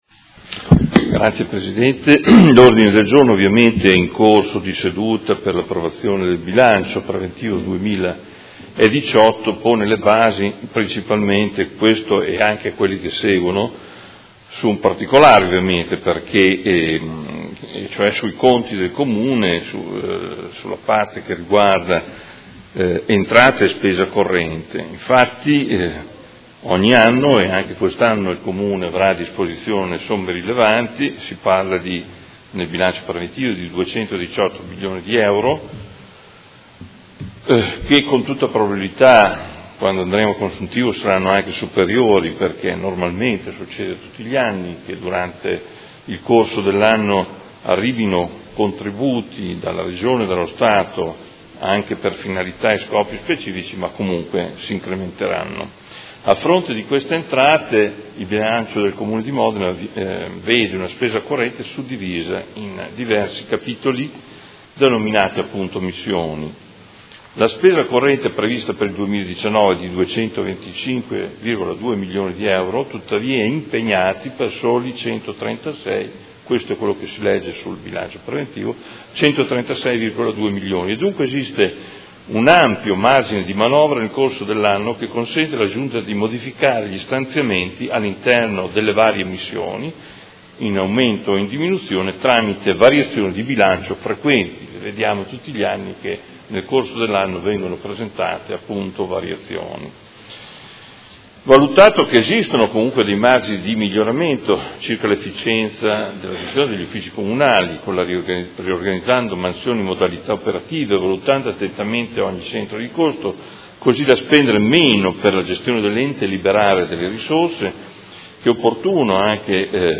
Seduta del 20/12/2018. Presenta Ordine del Giorno Prot. Gen. 211071